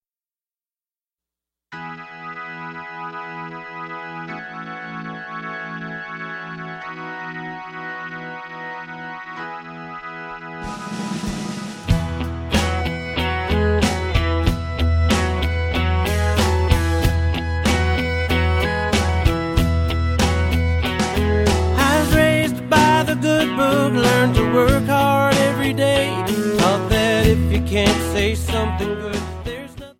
Backing track files: Country (2471)